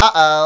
4:Oh-Ow!.mp3